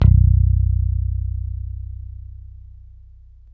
Index of /90_sSampleCDs/Roland L-CD701/BS _E.Bass 2/BS _Rock Bass